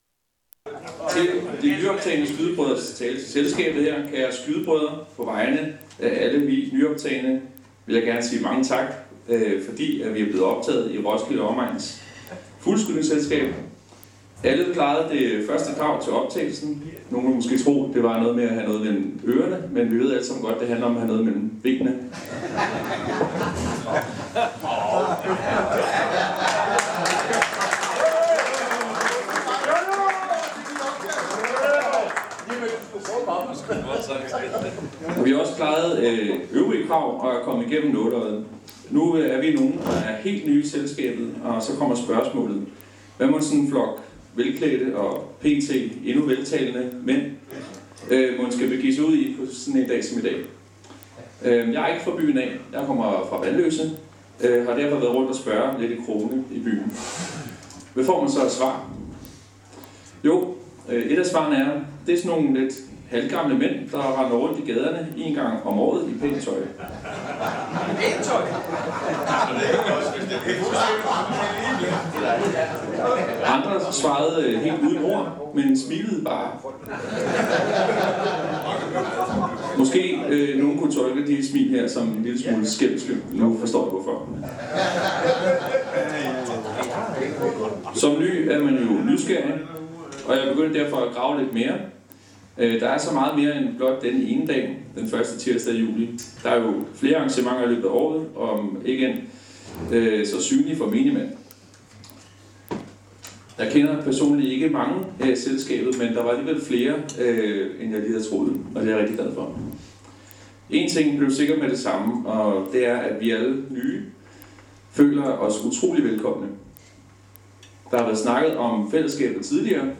tale for selskabet, på vegne af årets nyoptagne skydebrødre, blev videooptaget under fugleskydningen i 2024